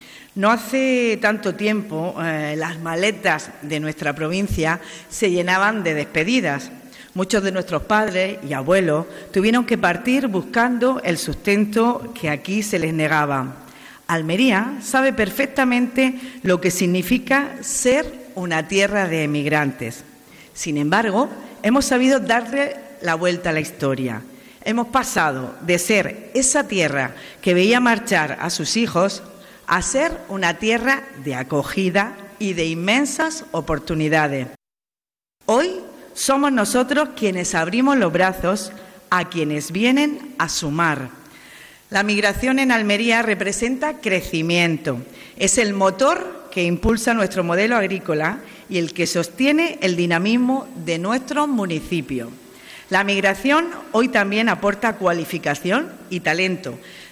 La diputada provincial Esther Álvarez ha dado la bienvenida a todos los participantes en esta jornada organizada por el Colegio de Graduados Sociales de Almería
18-12_jornada_migraciones_graduados_sociales_esther_alvarez.mp3